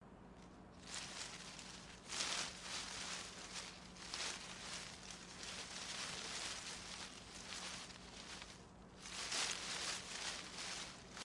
叶子
描述：沙沙作响
Tag: 沙沙 沙沙 灌木 叶子